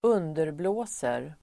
Uttal: [²'un:derblå:ser]